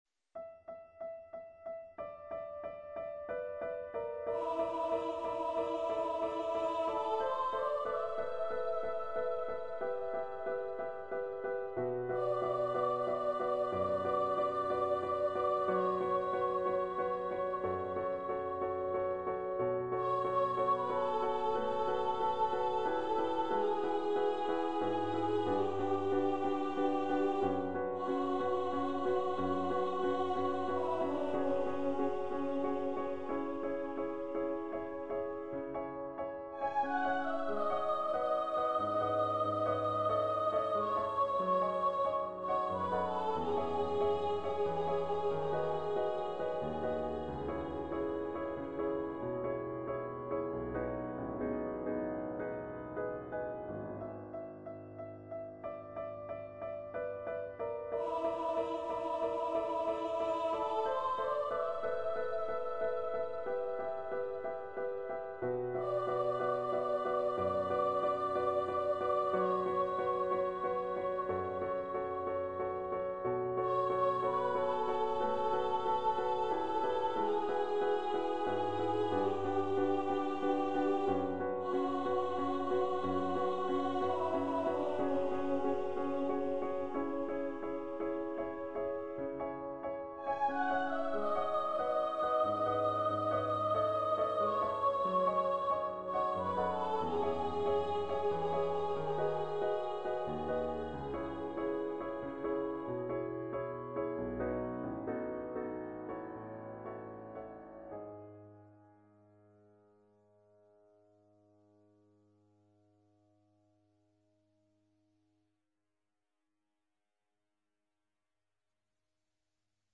Voice and Piano
Composer's Demo